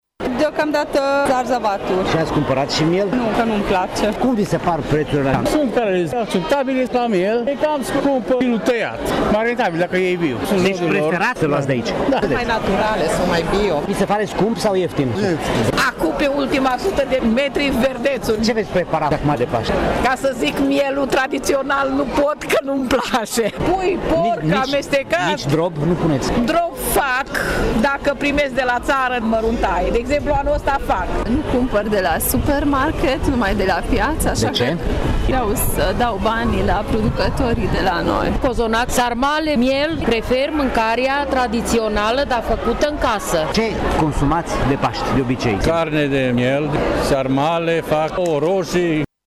Piața este în topul locurilor de unde se cumpără produsele de sezon pentru că, spun târgumureșenii, în hypermarketuri acestea sunt mai scumpe și nu sunt atât de naturale: